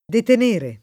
vai all'elenco alfabetico delle voci ingrandisci il carattere 100% rimpicciolisci il carattere stampa invia tramite posta elettronica codividi su Facebook detenere [ deten % re ] v.; detengo [ det $jg o ], detieni — coniug. come tenere